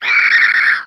MONSTERS_CREATURES
CREATURE_Squeel_05_mono.wav